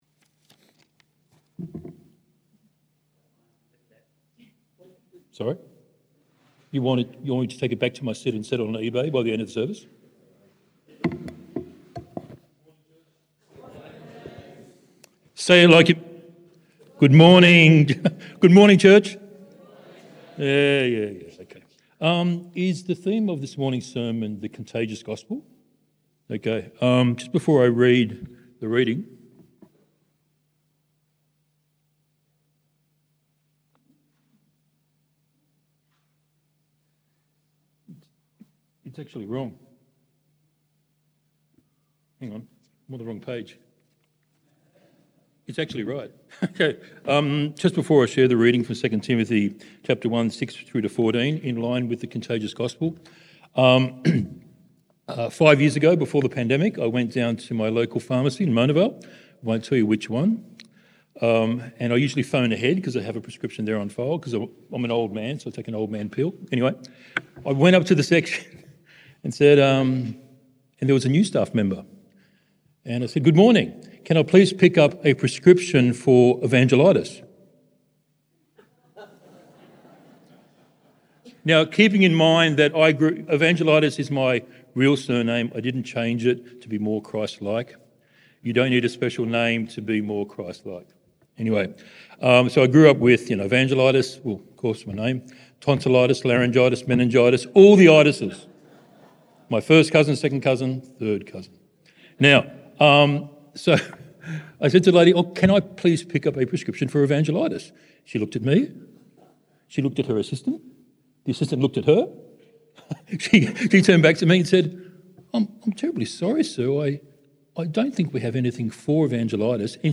October Messages Week 4 2 Timothy 1:6-14 Join us this month with a series of guest speakers and testimonies, looking at a variety of topics and biblical passages.